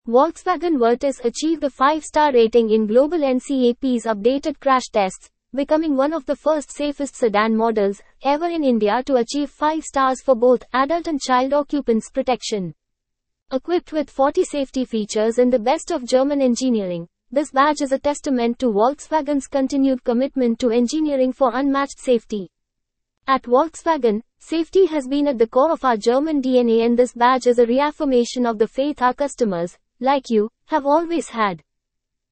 Volkswoganspeech.mp3